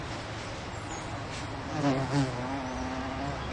夏天的昆虫 奇怪的嗡嗡声虫2
描述：夏天昆虫奇怪的嗡嗡声虫子
标签： 错误的 怪异的 昆虫 夏天 嗡嗡
声道立体声